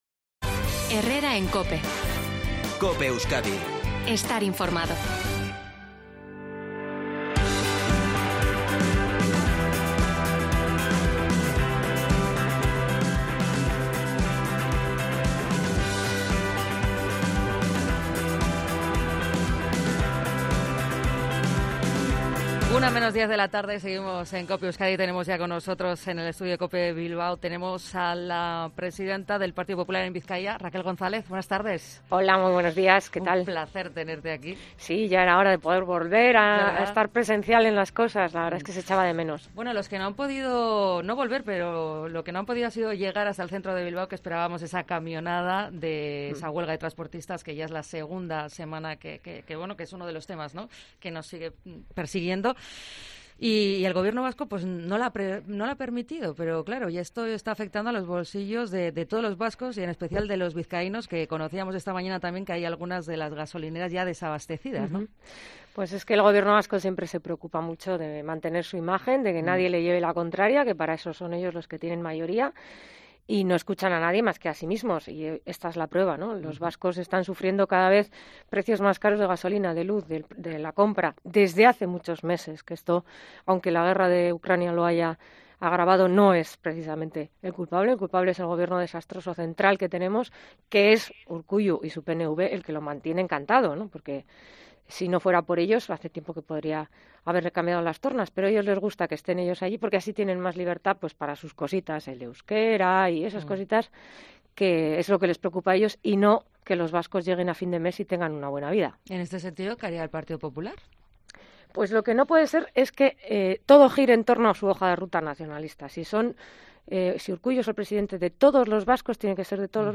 En una entrevista en COPE Euskadi, la portavoz de los populares vizcaínos se ha mostrado muy preocupada por la atención sanitaria que advierte es "cada vez peor" y acusa a la Consejera de Salud, Gotzone Sagardui, de hacer "oídos sordos" al desmantelamiento.